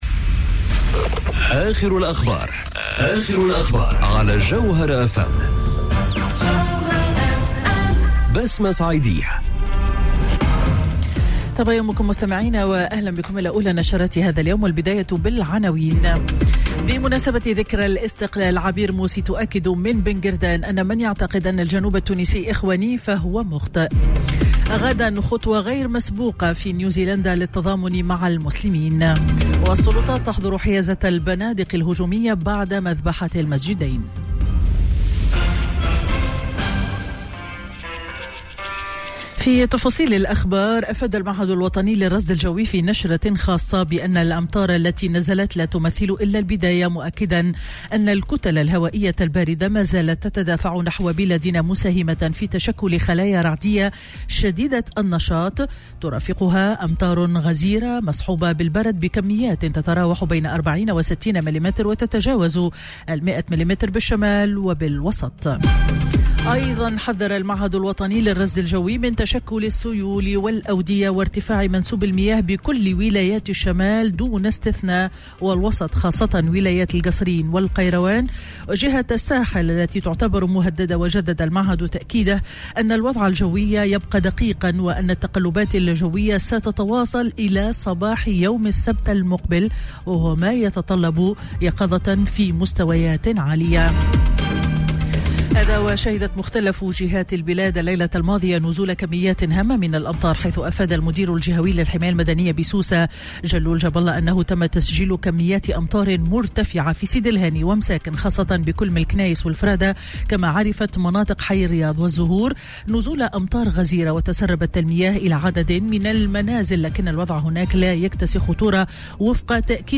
نشرة أخبار السابعة صباحا ليوم الخميس 21 مارس 2019